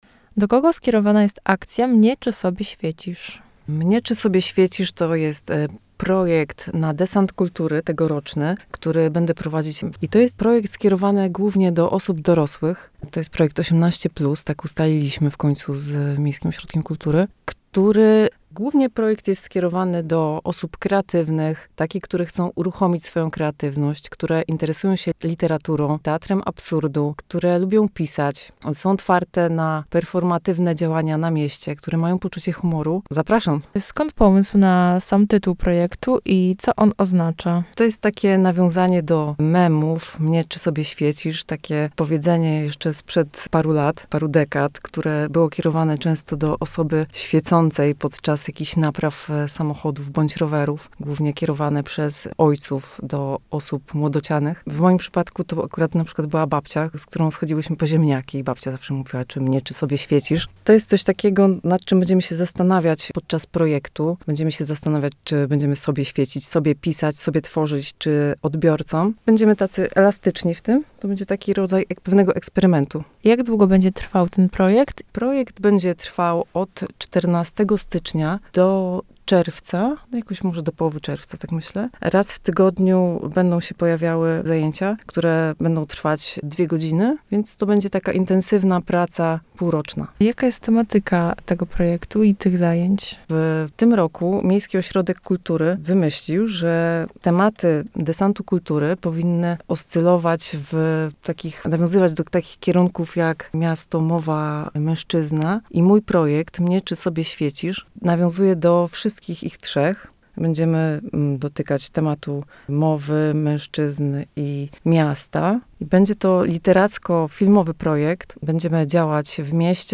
O tym, na czym chcą się skupić, opowiedzieli także w studiu Radia UWM FM.